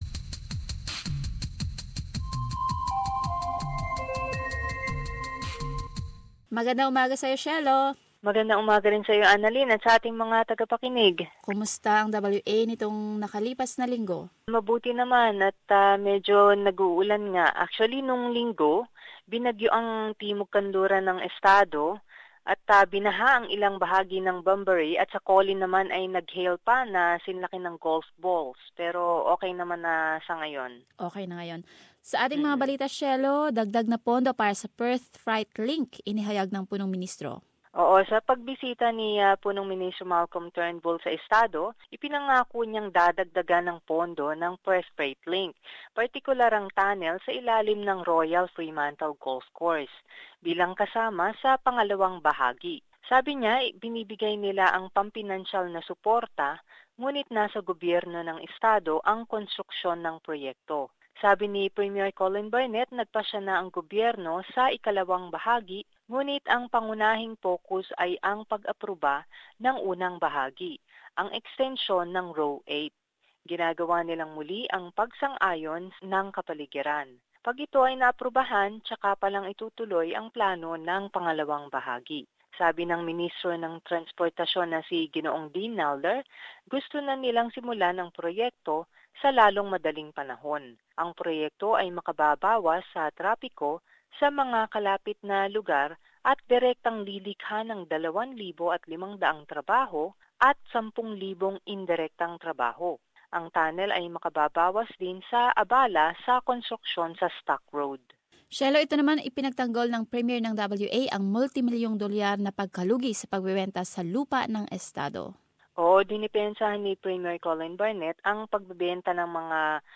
Summary of latest news from Western Australia